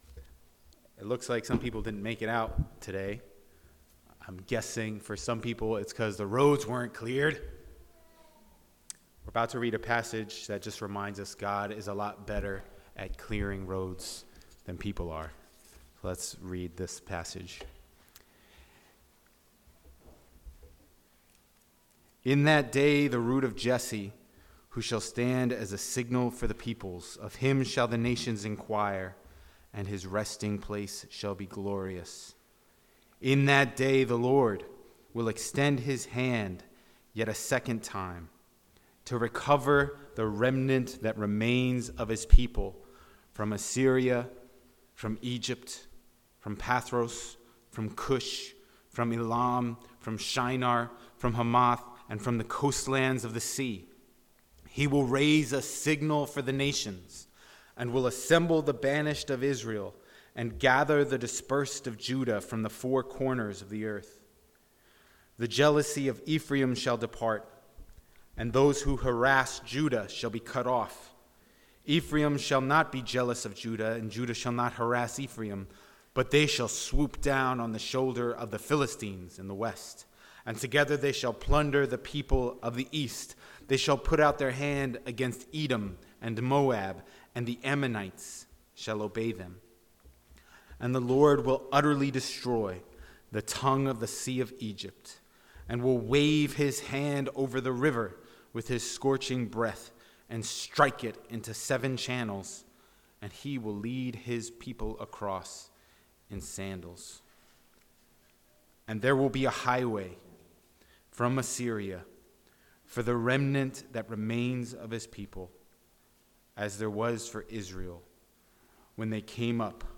Sermons | The Bronx Household of Faith